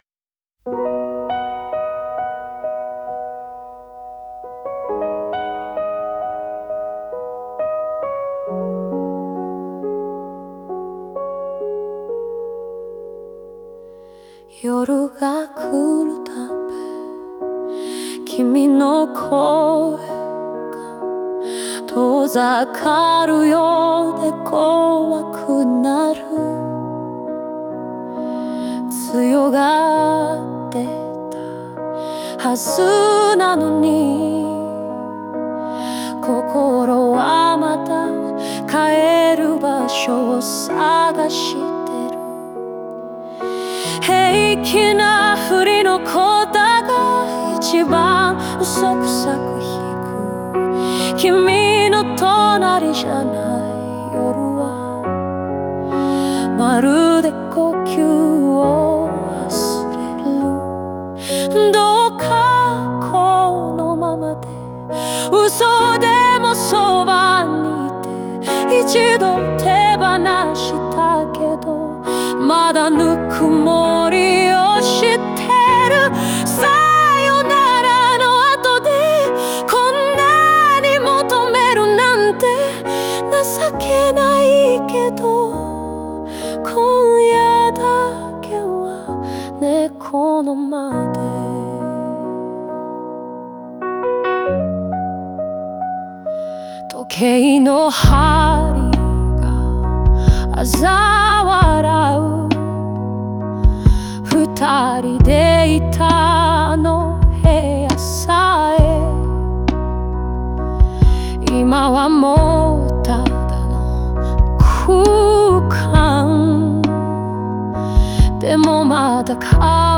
オリジナル曲♪
静かなピアノとソウルフルな歌声で、壊れてしまった関係の余熱を切なく表現しています。